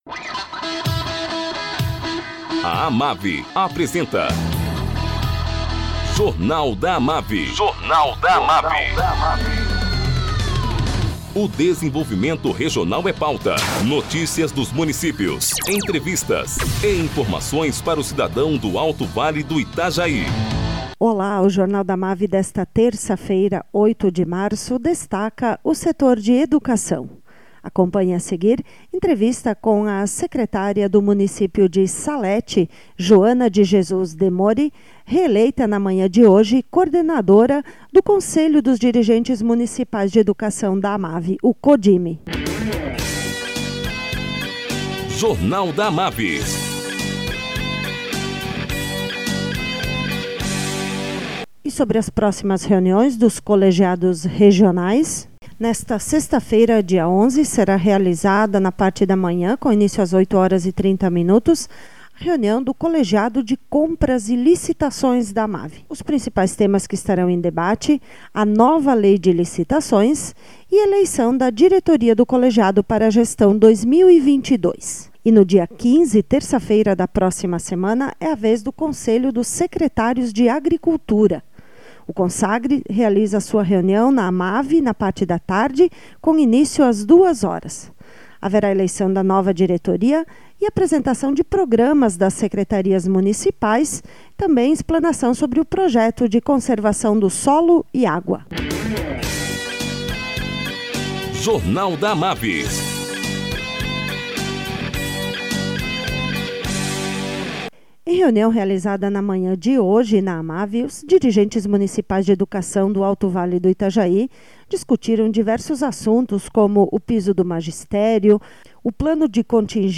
Entrevista com a secretária de educação de Salete e coordenadora do CODIME, Joana de Jesus Demori.